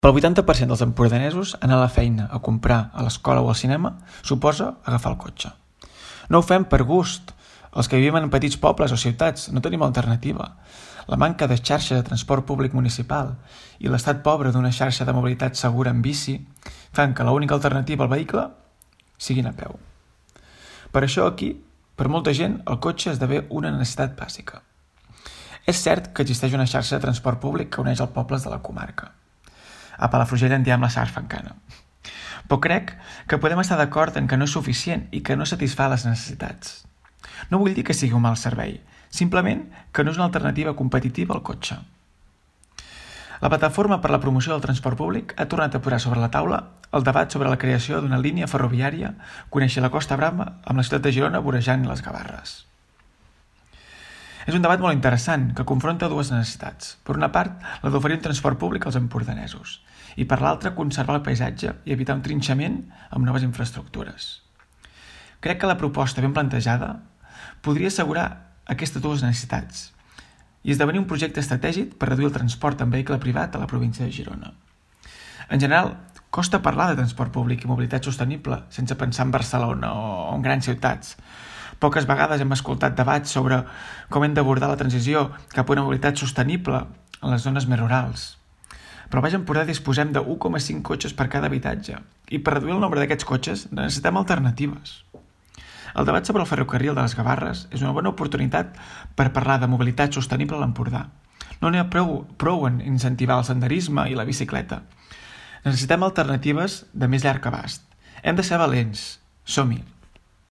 Supermatí - opinió